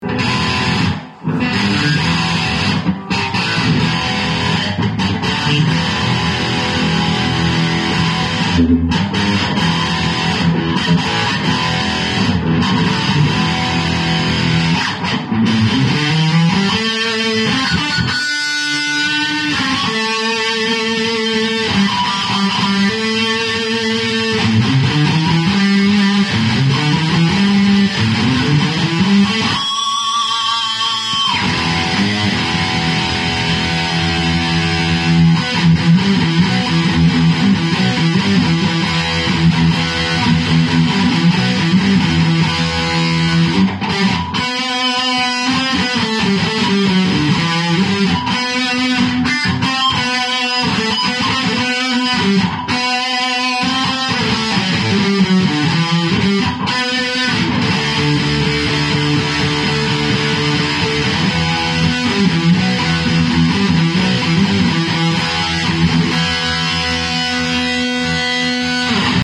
Houla la !!! Ça déchire !!!
Tout ce qu'il faut pour du Hard métal.
guitare-1er-electrifiee-essai2.mp3